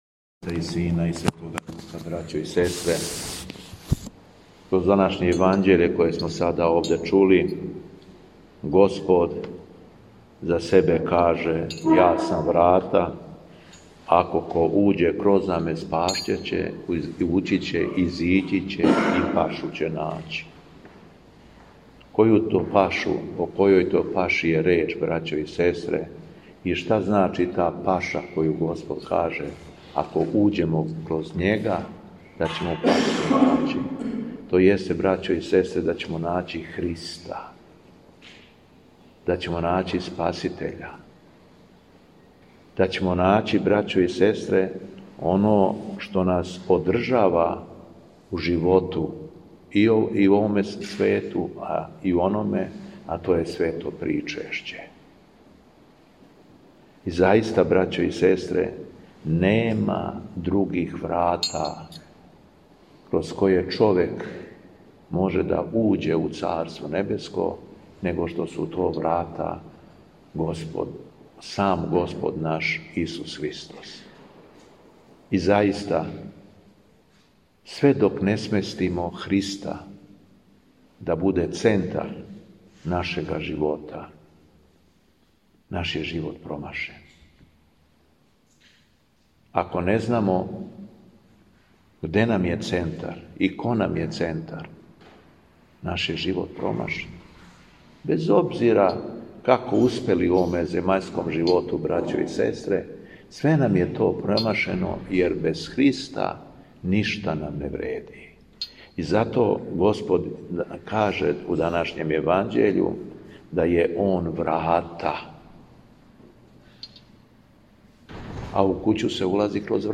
Беседа Његовог Високопреосвештенства Митрополита шумадијског г. Јована
Дана 15. октобра 2025. године Светог свештеномученика Кипријана и Светог мученка Јустина, Његово високопреосвештенство Митрополит шумадијски Господин Јован началствовао је свештеним евхаристијским сабрањем у крагујевачком насељу Бресница у храму Светог Јоаникија Девичког.